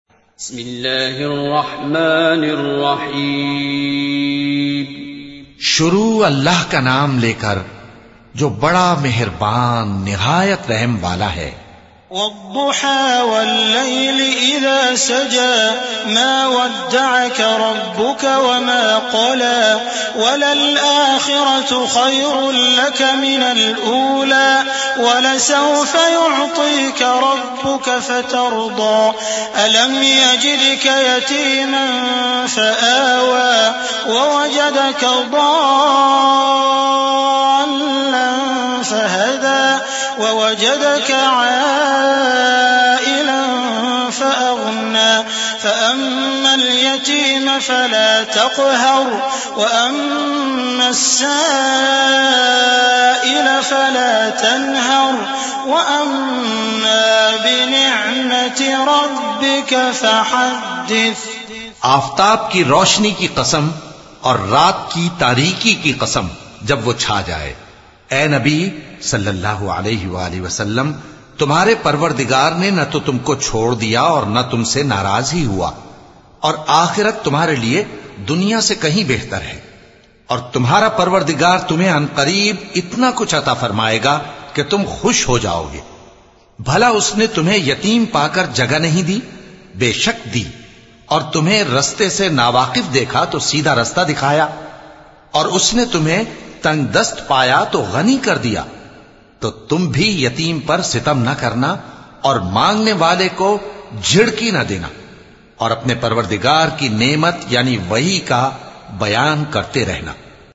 اللغة الأردية التلاوة بصوت الشيخ على عبد الرحمن الحذيفى